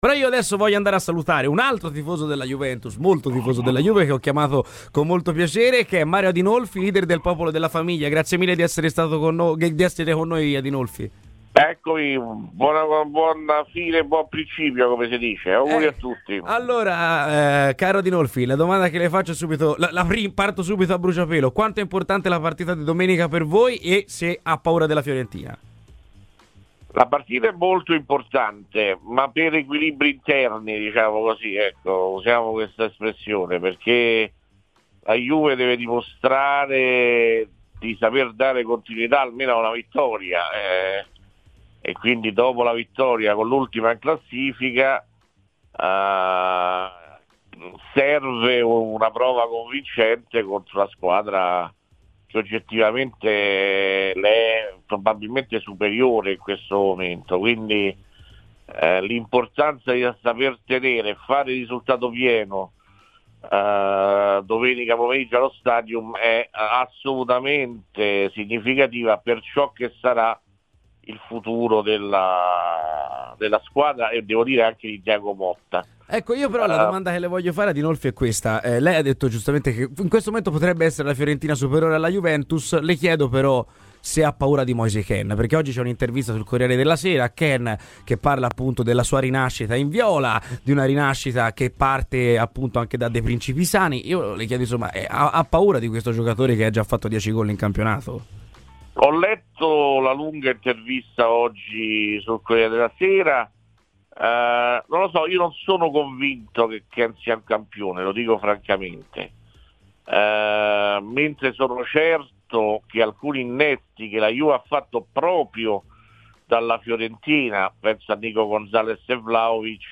Mario Adinolfi, leader del "Popolo della famiglia" nonché grande tifoso della Juventus, ha parlato a Radio FirenzeViola durante "C'è polemica" per commentare la partita di domenica tra i bianconeri e la Fiorentina: "La partita è molto importante, ma per equilibri interni.